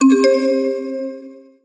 シンプルな通知音。